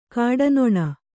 ♪ kāḍanoṇa